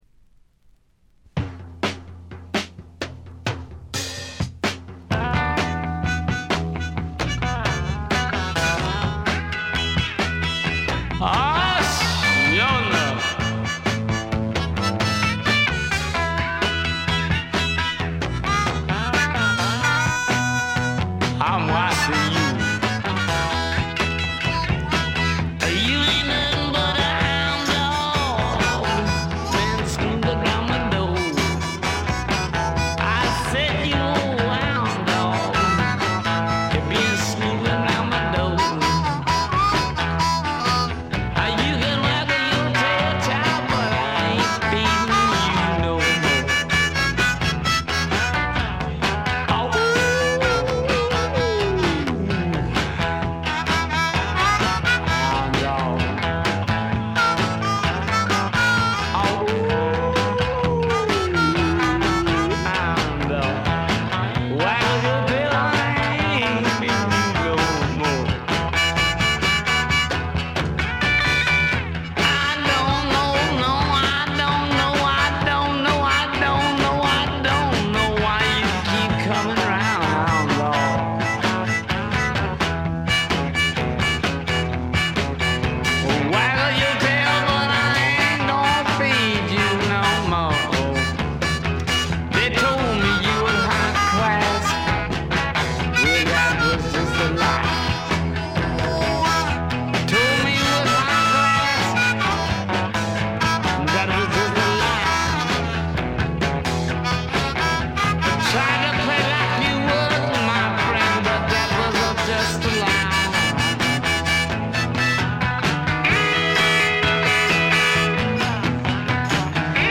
ほとんどノイズ感無し。
文句なしのスワンプ名盤。
試聴曲は現品からの取り込み音源です。